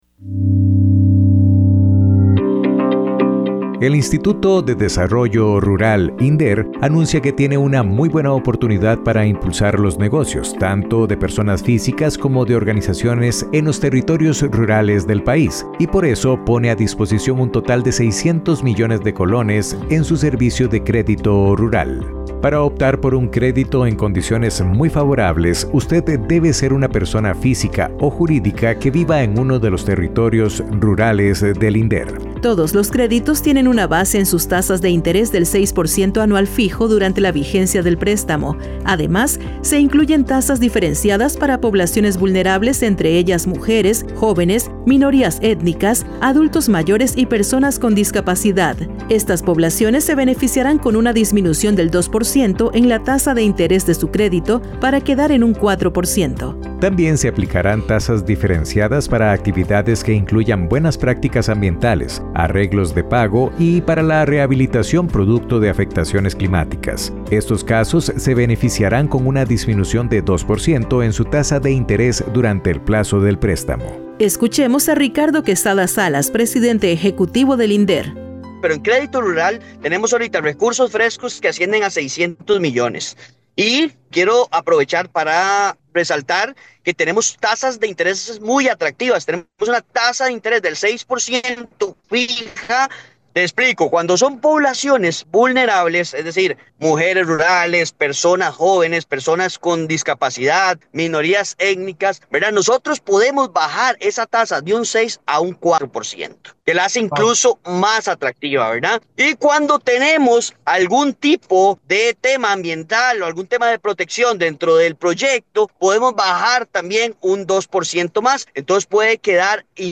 Escuchemos a Ricardo Quesada Salas, presidente ejecutivo del INDER.